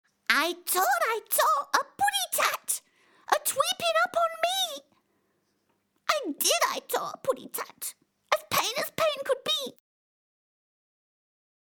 Voix off
- Soprano